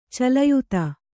♪ calayuta